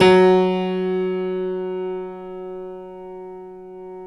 Index of /90_sSampleCDs/Optical Media International - Sonic Images Library/SI1_Six Pianos/SI1_Distantpiano